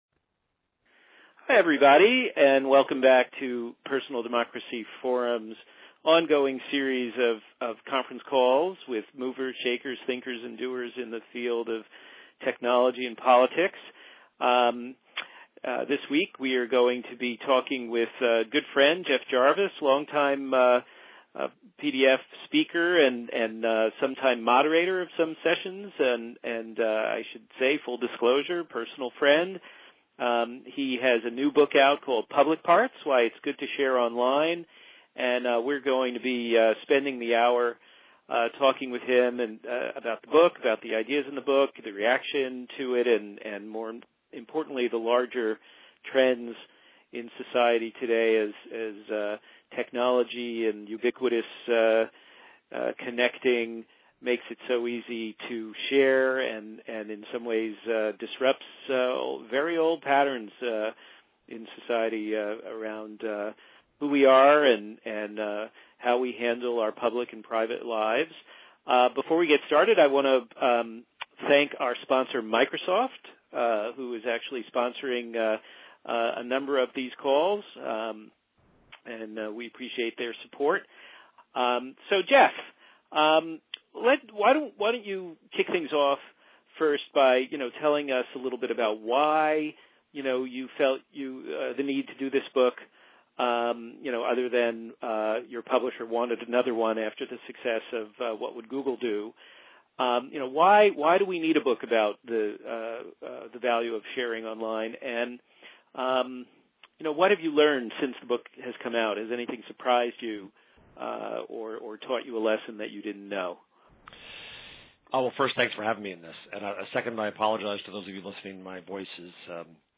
Tele-Conference with Jeff Jarvis, Author of Public Parts-2.mp3